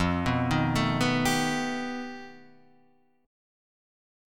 F 9th Flat 5th